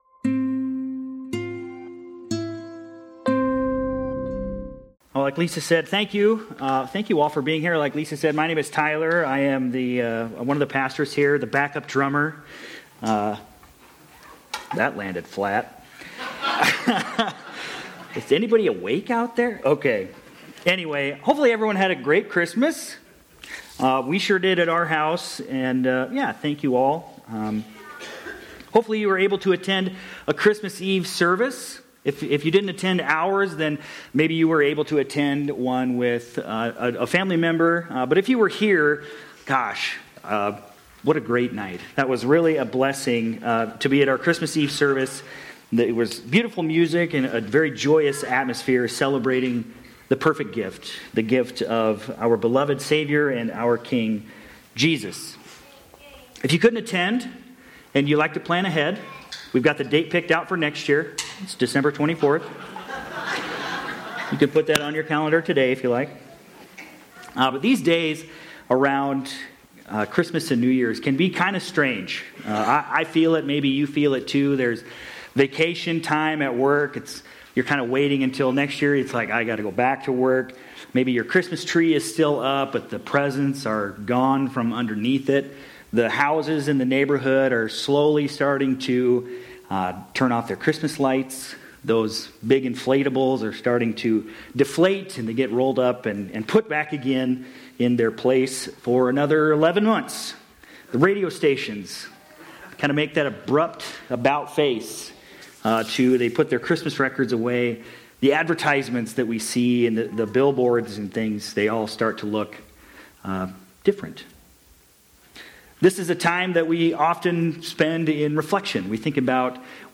Dec-28-25-Sermon-Audio.mp3